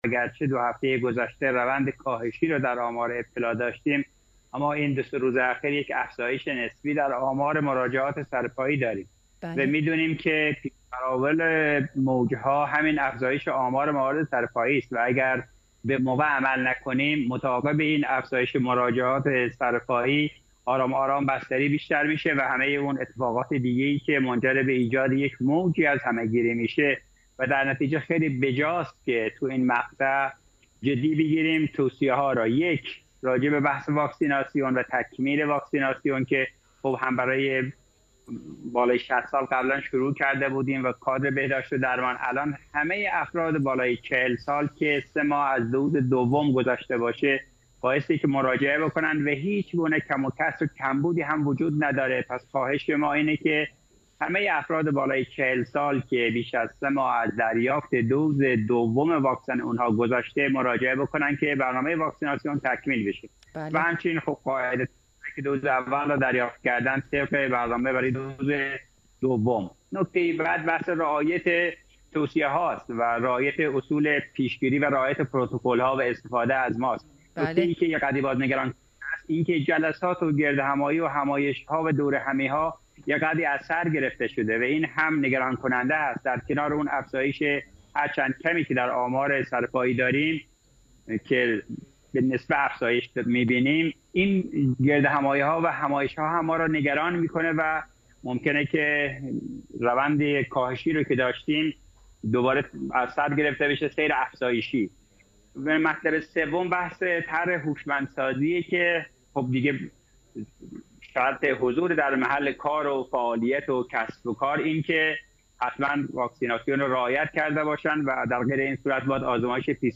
مصاحبه خبر ۲۰ سیمای استان- شامگاه دوشنبه ۲۲ آذرماه